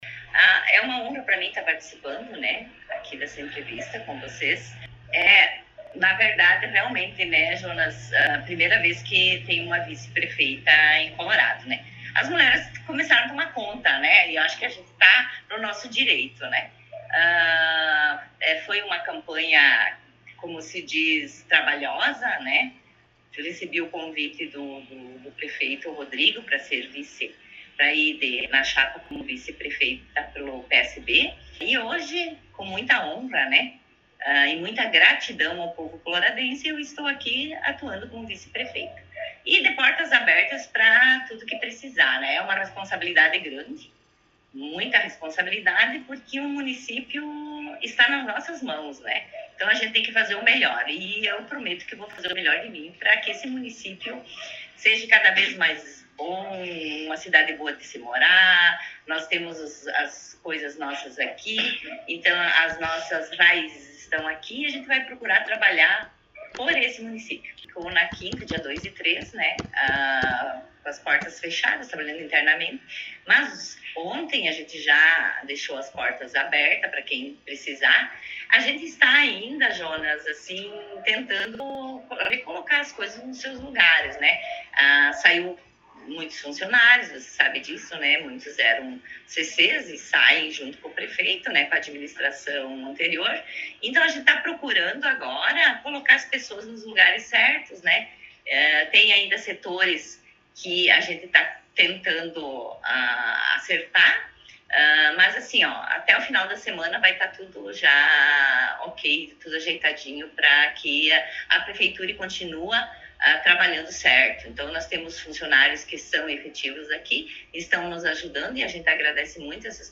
Vice-Prefeita Marta Mino concedeu entrevista
Na última terça-feira ( 7 ) estivemos na sala da Vice-prefeita Martinha para sabermos as informações dos seus trabalhos, do seus atendimentos e sobre assuntos da Administração Municipal. Na oportunidade a vice-prefeita falou sobre a honraria de exercer esse cargo ( aliás a Marta é a primeira mulher a exercer esse cargo em nosso município ), explicou sobre o trabalho interno que ocorreu na semana que passou, a falta de funcionários em alguns setores, falou sobre a experiência em ter sido vereadora e em ter anos de trabalho na área da saúde e falou sobre o horário do seu atendimento na prefeitura e de seu comprometimento com a população .